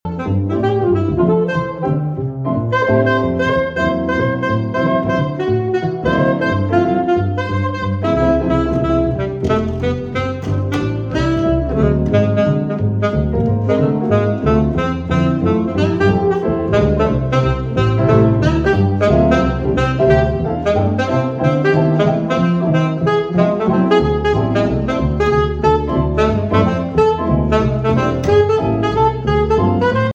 bass
keys